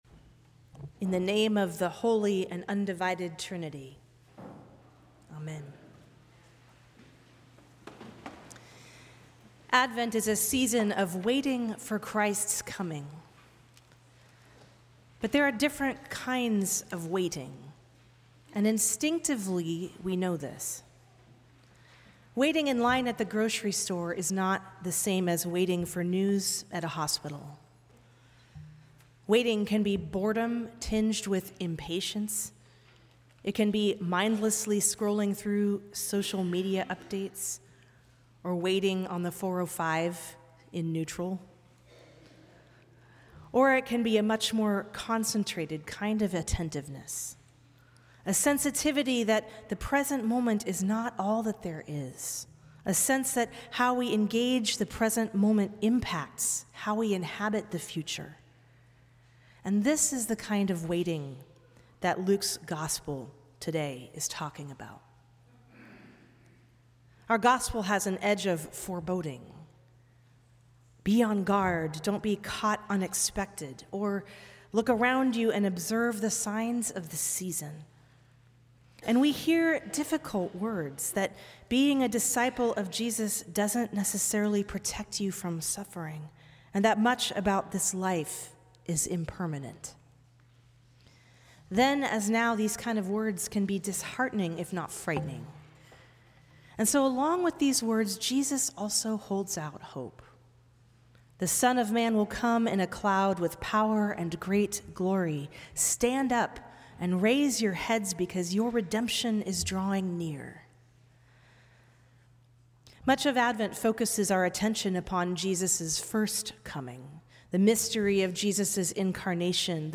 Sermons from St. Cross Episcopal Church First Sunday of Advent Dec 01 2024 | 00:10:08 1x 00:00 / 00:10:08 Subscribe Share Apple Podcasts Spotify Overcast RSS Feed Share Link Embed